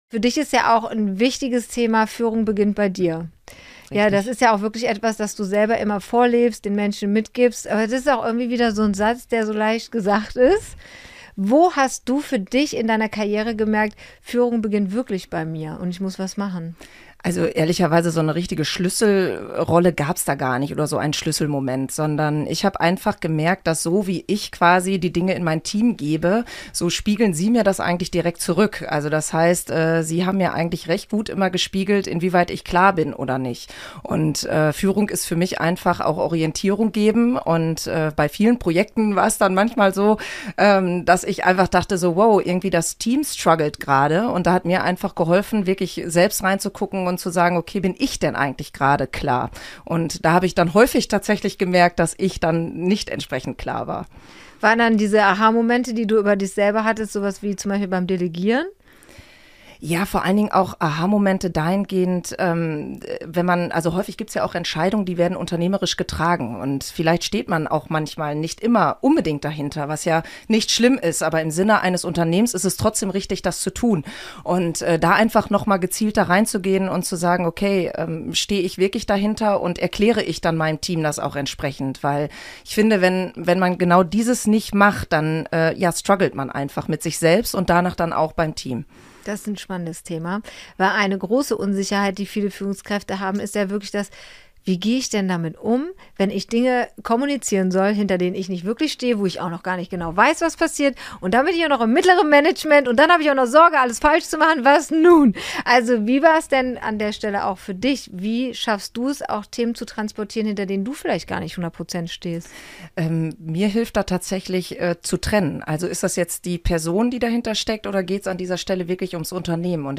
Gedreht wurde im Eventflugzeug auf dem euronova Campus in Hürth.